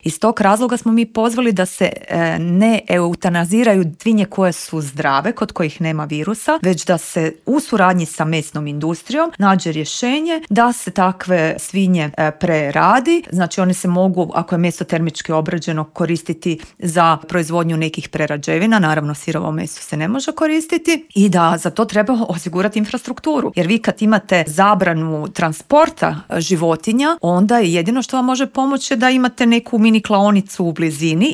U popodnevnim satima sastaje se i saborski Odbor za poljoprivredu čija je predsjednica Marijana Petir gostovala u Intervjuu Media servisa: